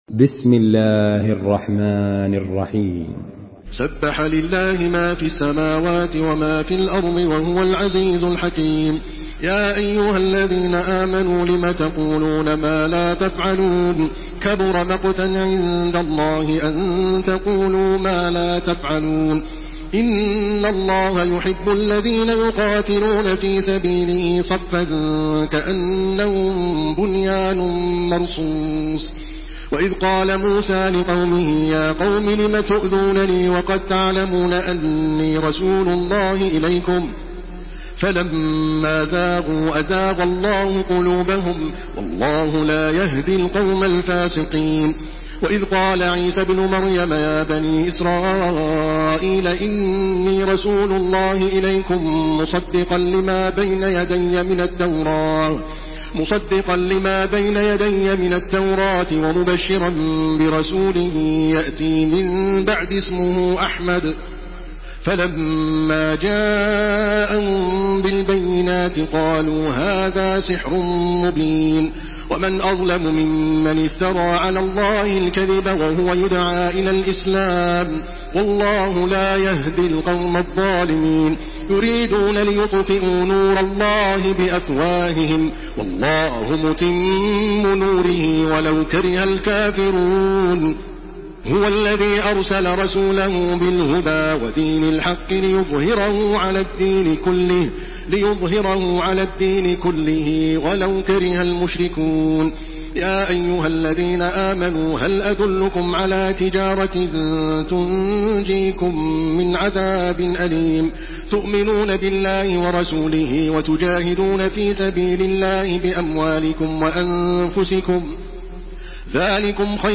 Makkah Taraweeh 1429
Murattal